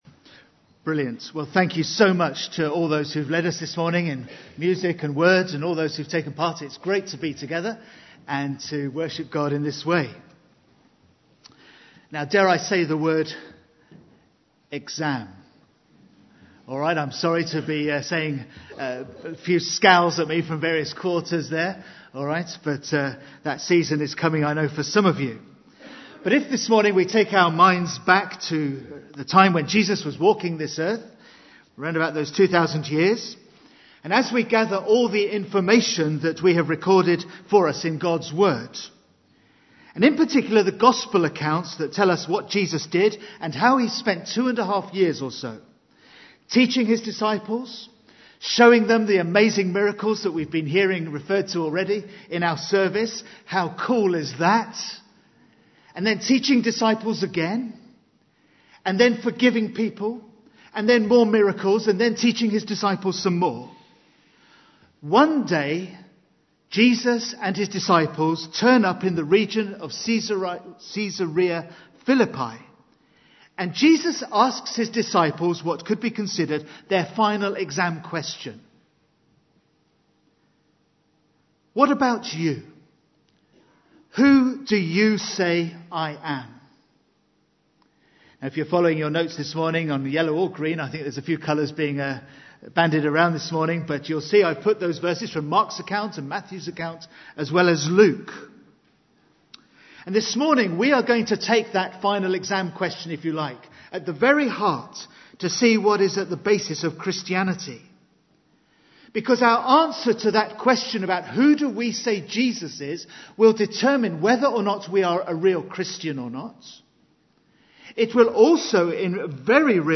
John 8:57-58) – His fullness of the Deity (Col 2:9) Preached on: 22 February, 2009 Service type: Sunday AM Bible Text: Colossians 1:15-20; Colossians 2:6-12